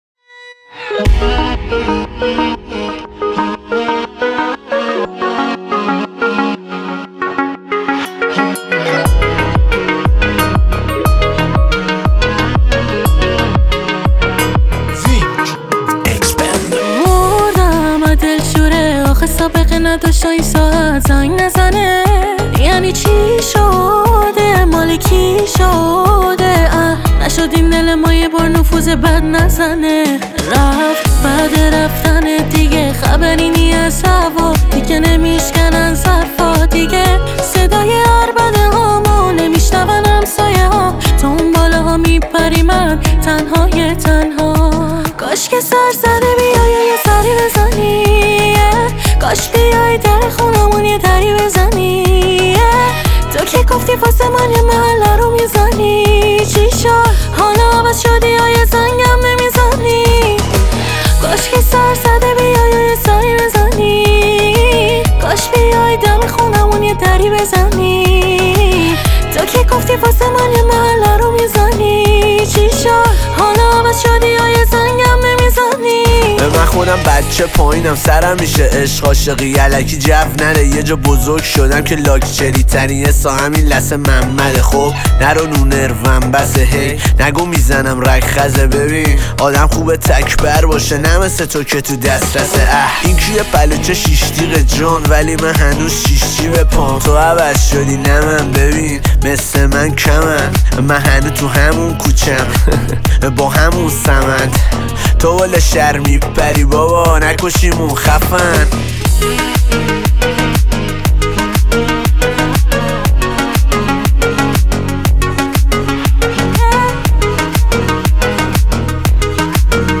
سبک خوانندگی پاپ